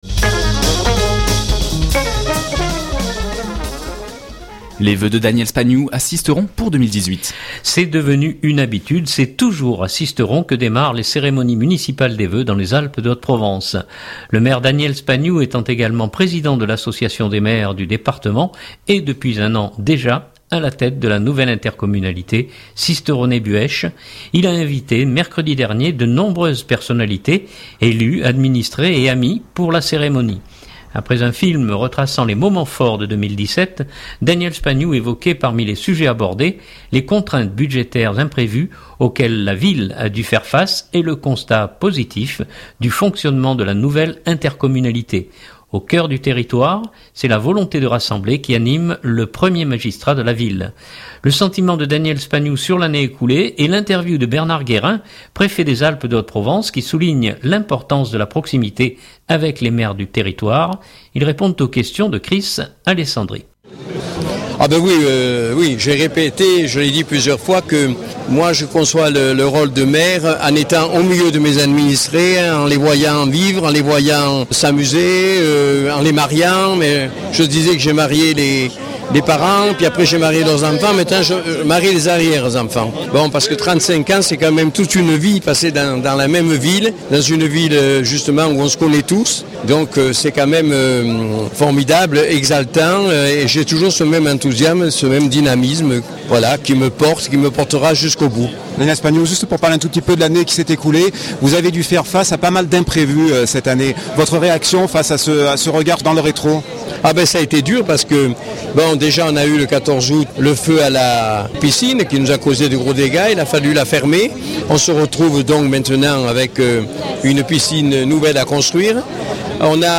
Le sentiment de Daniel Spagnou sur l’année écoulée et l’interview de Bernard Guérin, Préfet des Alpes de Haute-Provence qui souligne l’importance de la proximité avec les maires du territoire.